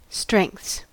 Ääntäminen
Ääntäminen US : IPA : [stʃɹɛŋkθs] Tuntematon aksentti: IPA : /ˈstɹɛŋθs/ IPA : /ˈstɹɛŋkθs/ Haettu sana löytyi näillä lähdekielillä: englanti Käännöksiä ei löytynyt valitulle kohdekielelle.